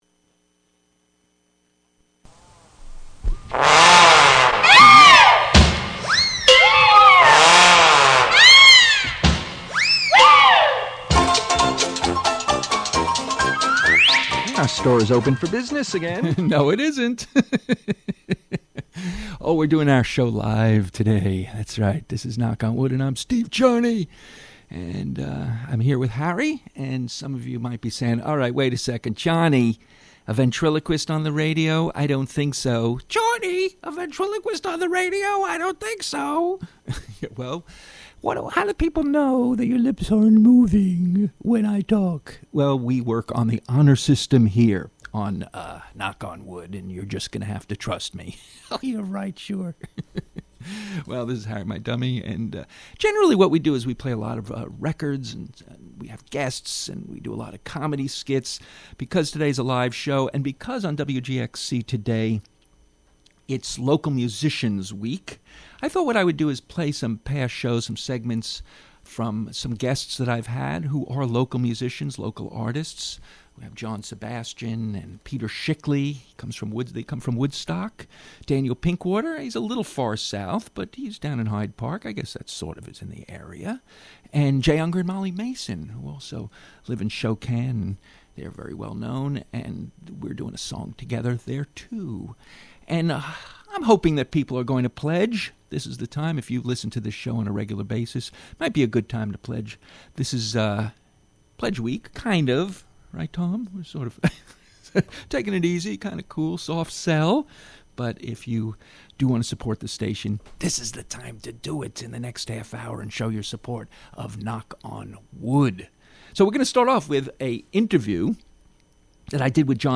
Knock On Wood Comedy Show
Ducks-Daniel Pinkwater Reads His Book